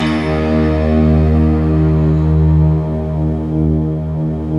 SI1 PLUCK02L.wav